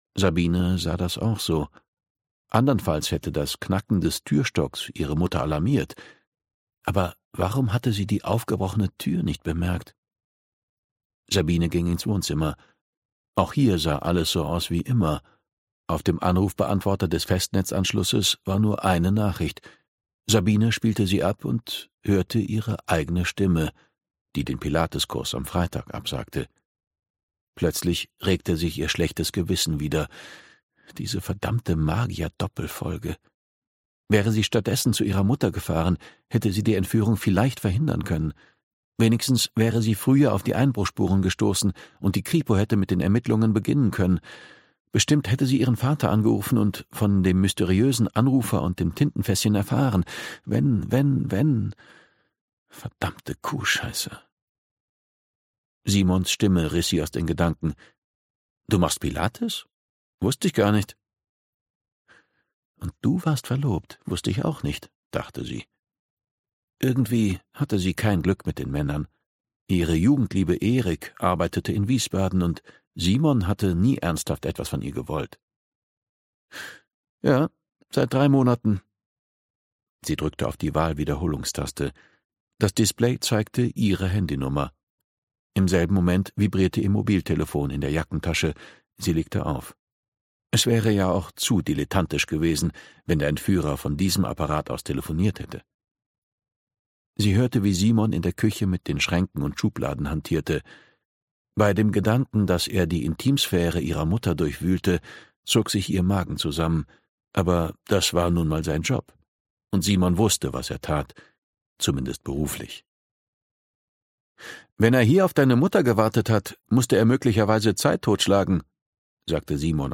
Hörbuch: Todes-Box.
Gekürzte Lesung